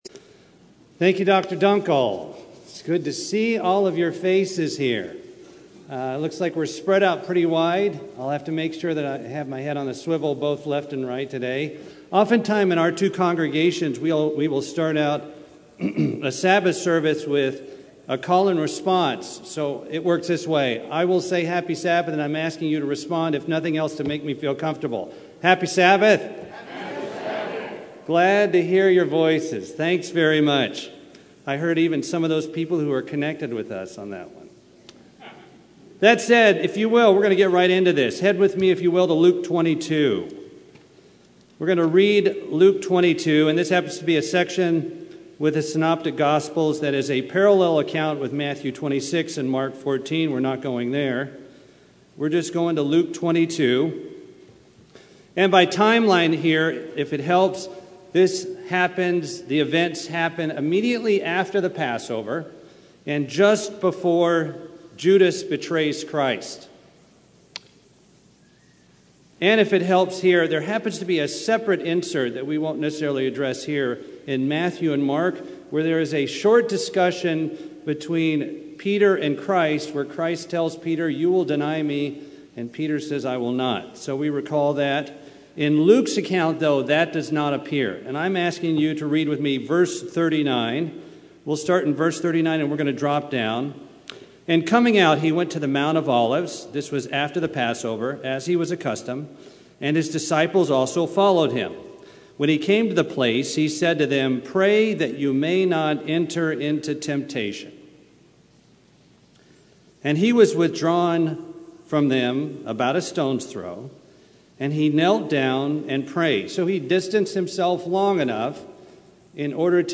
UCG Sermon Fathers sons Paul timothy Family and Youth Concern family seek Communication respect trust love Relationship with God growth Studying the bible?